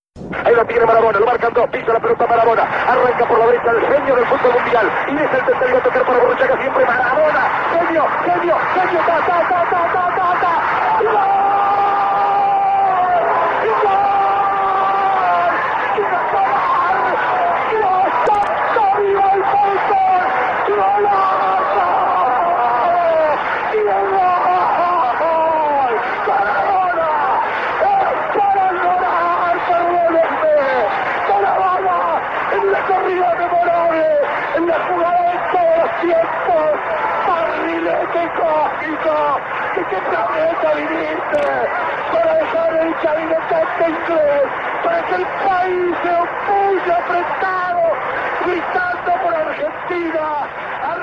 Unten findet Ihr die Reportagen zu acht berühmten Toren, bei denen sich die Kommentatoren besonders ins Zeug gelegt haben, verbunden mit jeweils einer Frage.